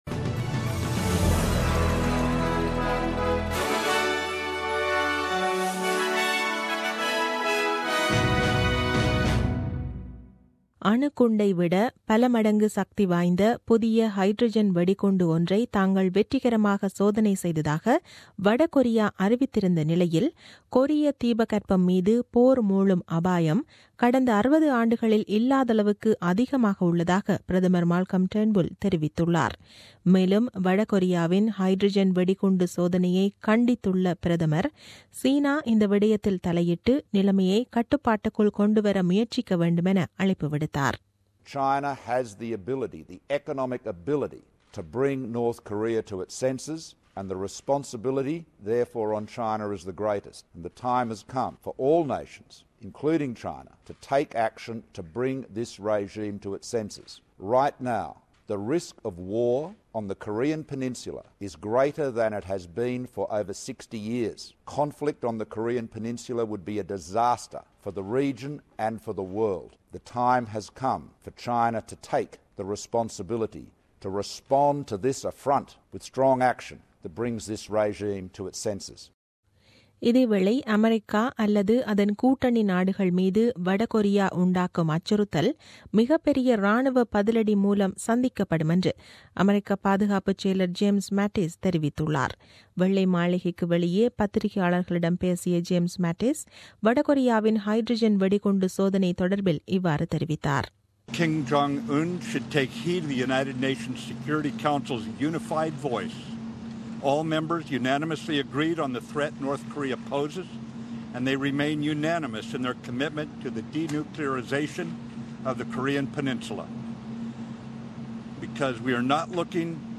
The news bulletin aired on 04 Sep 2017 at 8pm.